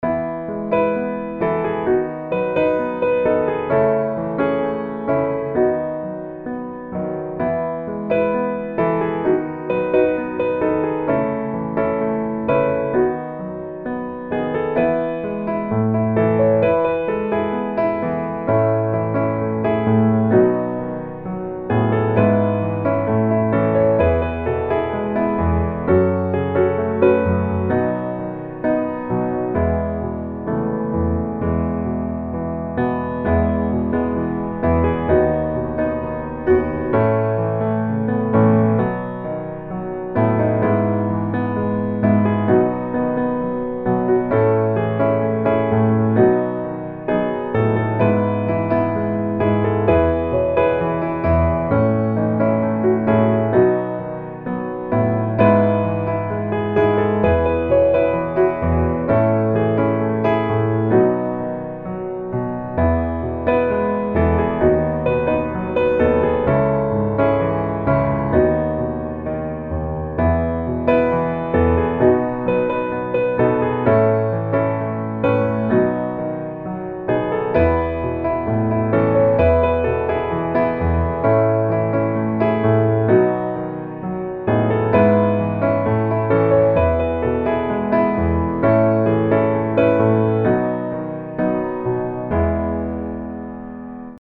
E Major